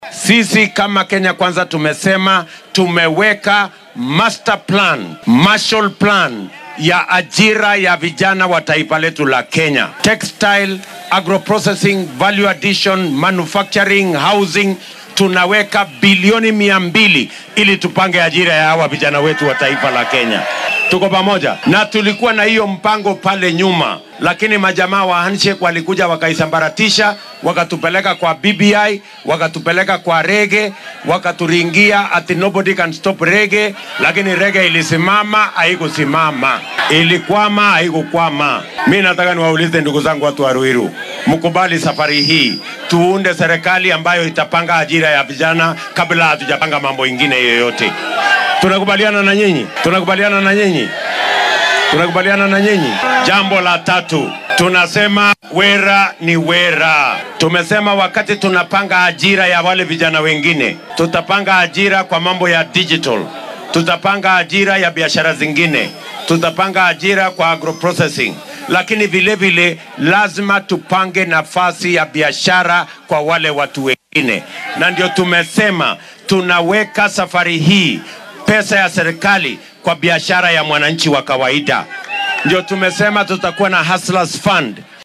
Musharraxa madaxweyne ee isbeheysiga Kenya Kwanza ahna madaxweyne ku xigeenka dalka William Ruto ayaa maanta isku soo bax siyaasadeed ku qabtay Limuru oo ka tirsan ismaamulka Kiambu. Waxaa uu ballanqaaday in haddii hoggaanka uu qabto maamulkiisa uu taageero dhaqaale siin doono ganacsatada yaryar iyo dhallinyarada oo shaqo abuur loo sameyn doono. Sidoo kale waxaa uu mar kale soo hadal qaaday qorshihii xeer beddelka ee BBI ee fashilmay.